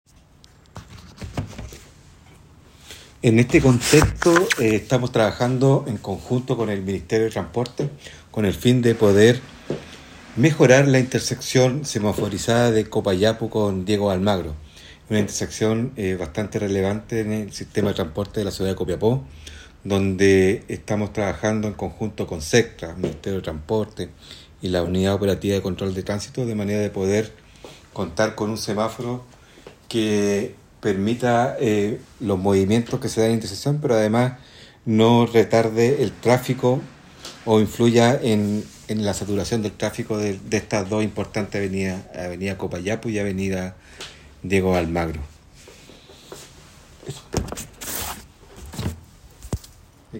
Entrevista a Director Serviu